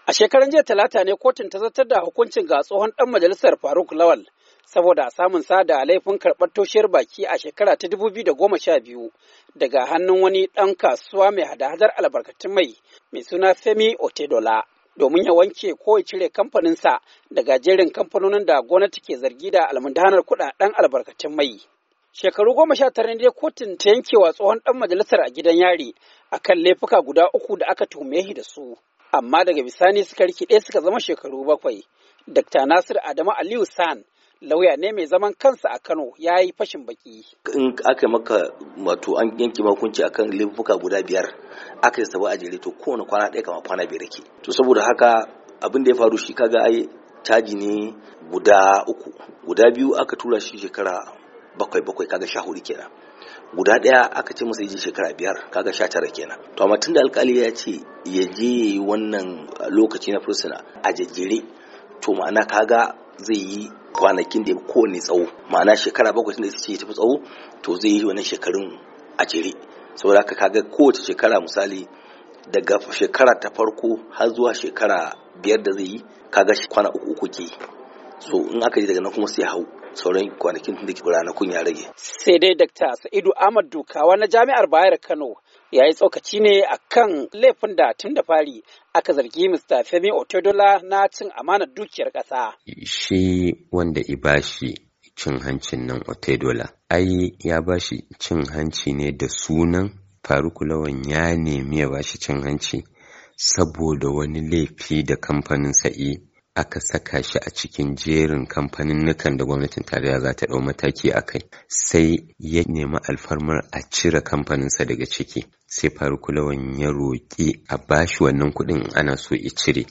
Saurari rahoto cikin sauti